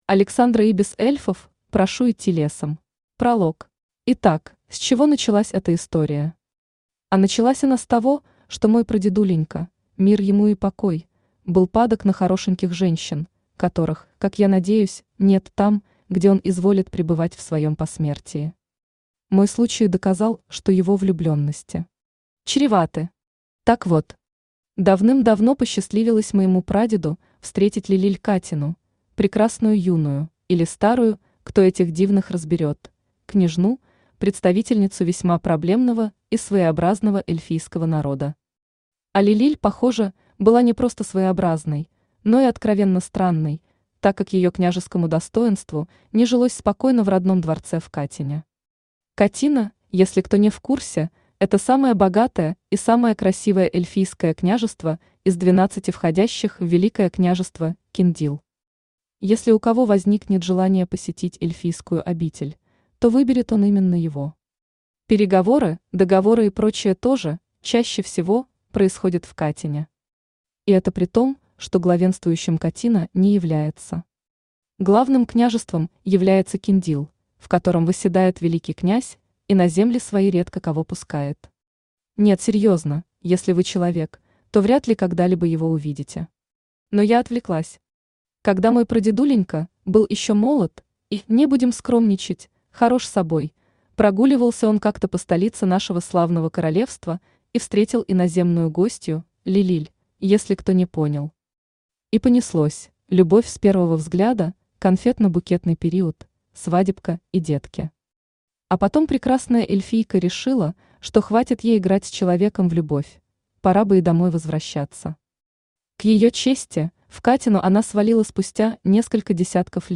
Аудиокнига Эльфов прошу идти лесом | Библиотека аудиокниг
Aудиокнига Эльфов прошу идти лесом Автор Александра Ибис Читает аудиокнигу Авточтец ЛитРес.